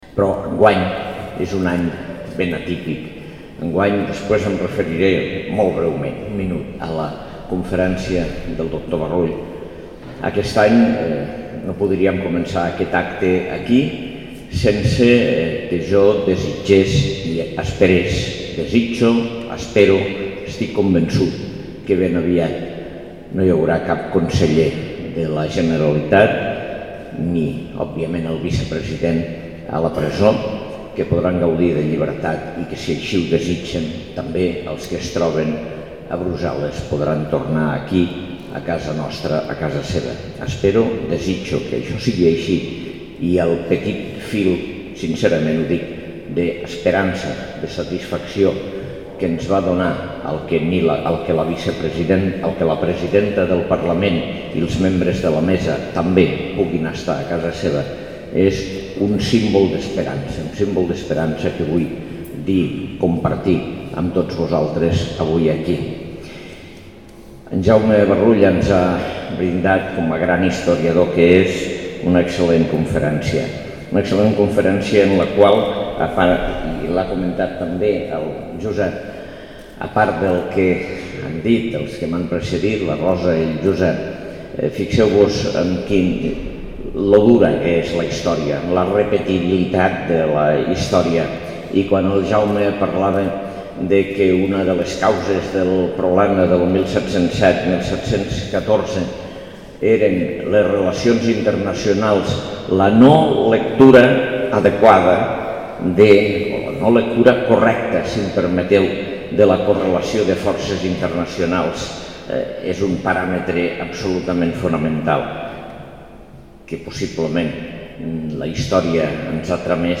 L’alcalde Ros, que ha presidit l’acte commemoratiu a la Seu Vella per recordar els fets de l’11 de novembre de 1707, ha avançat que l’any 2018 se celebrarà que farà 100 anys que la Seu Vella va ser declarada Monument Històric Artístic Nacional, quan era paer en cap de la ciutat el doctor Humbert Torres
La Seu Vella de Lleida ha acollit aquest dissabte l’acte commemoratiu per recordar els fets de la caiguda de Lleida l’11 de novembre de 1707 en la Guerra de Successió.